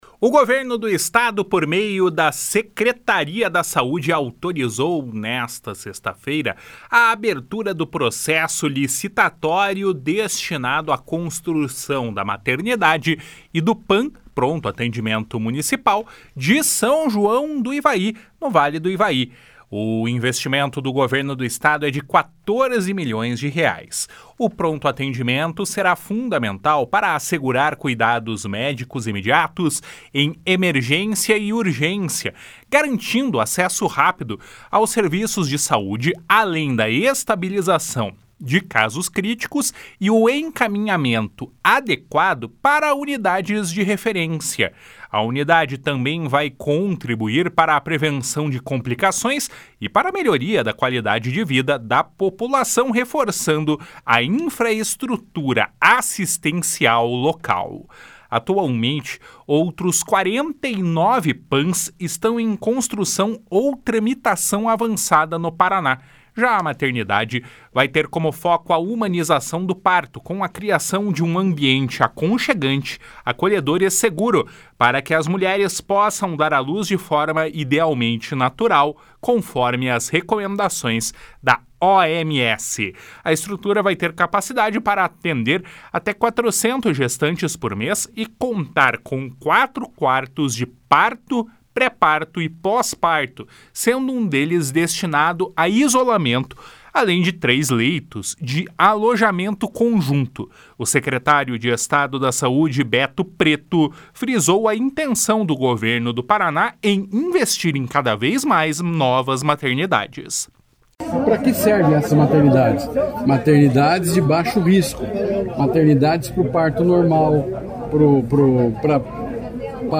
O secretário de Estado da Saúde, Beto Preto, frisou a intenção do Governo do Paraná em investir em cada vez mais novas maternidades.